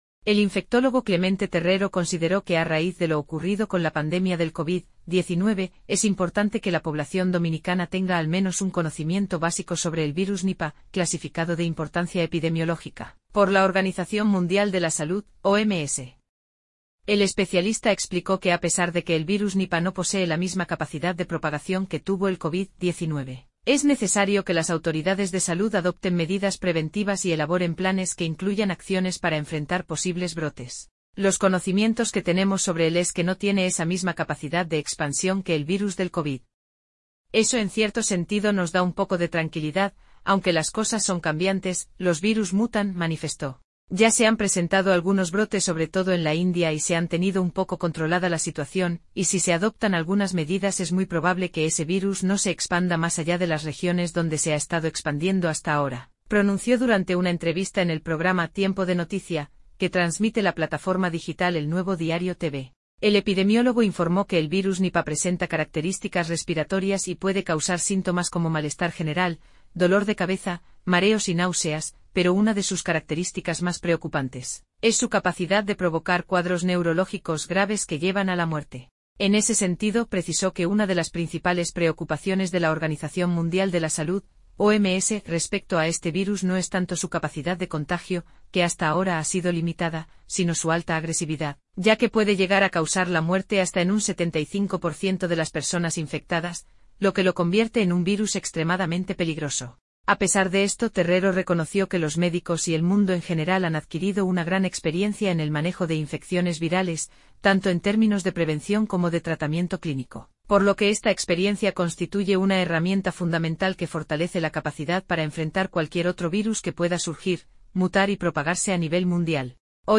infectólogo
“Ya se han presentado algunos brotes sobre todo en la India y se han tenido un poco controlada la situación y si se adoptan algunas medidas es muy probable que ese virus no se expanda más allá de las regiones donde se ha estado expandiendo hasta ahora”, pronunció durante una entrevista en el programa “Tiempo de Noticia”, que transmite la plataforma digital El Nuevo Diario TV.